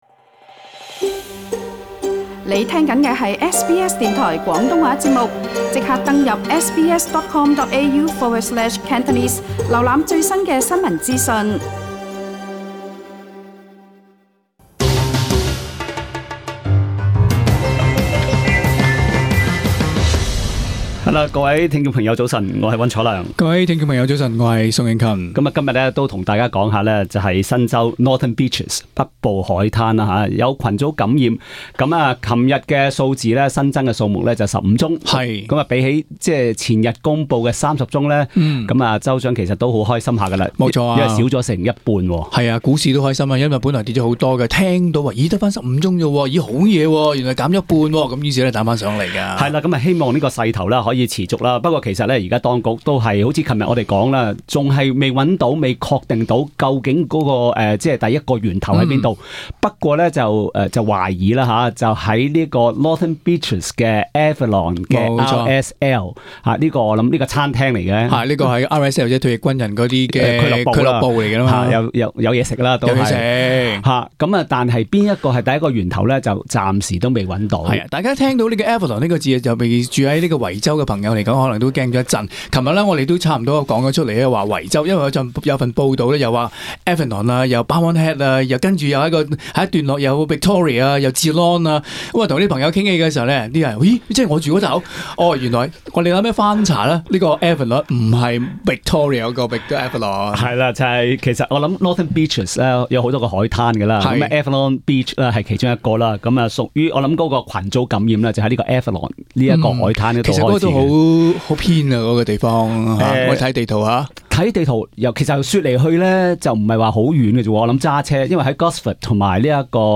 Source: AAP SBS广东话播客 View Podcast Series Follow and Subscribe Apple Podcasts YouTube Spotify Download (13.16MB) Download the SBS Audio app Available on iOS and Android 新州单日新增 8 宗新冠确诊，比昨日的 15 宗减半，成绩尚算不错。